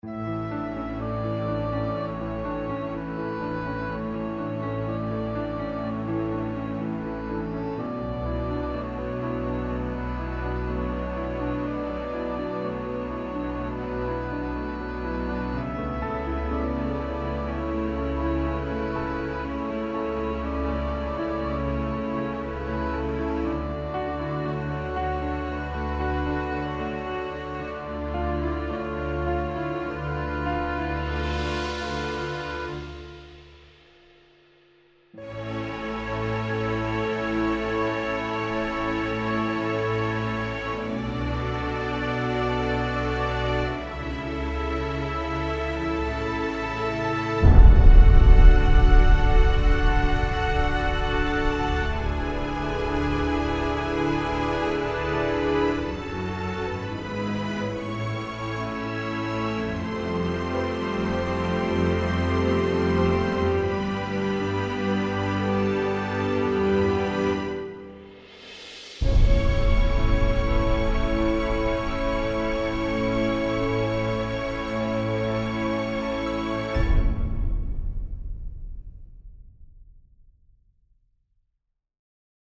piano - intimiste - romantique - aerien - melodieux